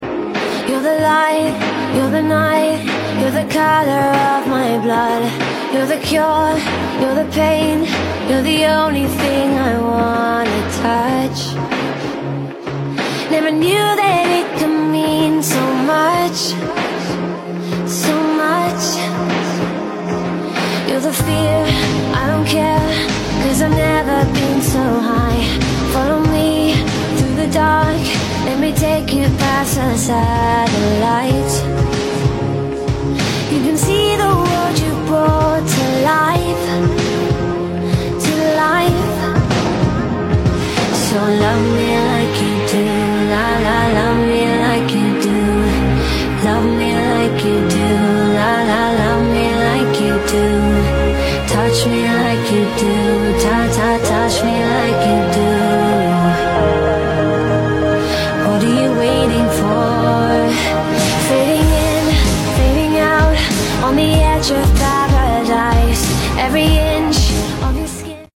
Monkey Has A Sore Leg Sound Effects Free Download